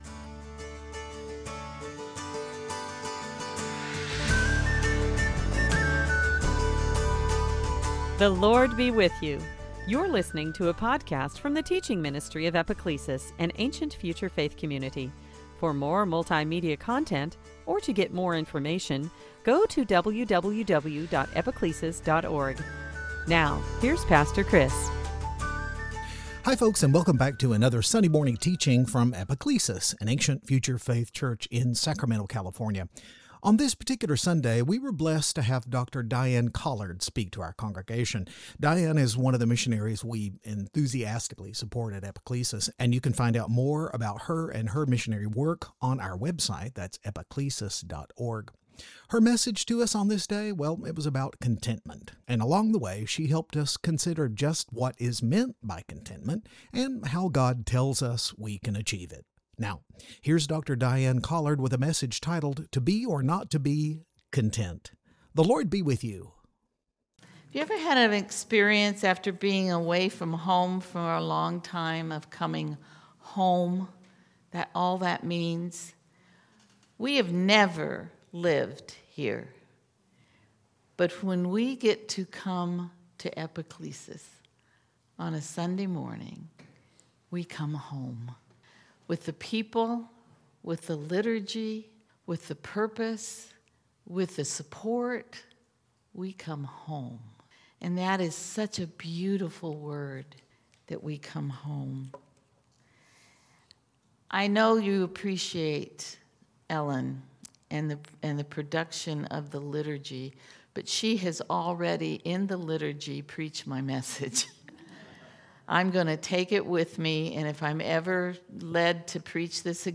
The artwork associated with this podcast/sermon is by Eva Crawford and is titled “More Than Conquerors,” 2018.
Phil 4:11-13 Service Type: Lent On this Sunday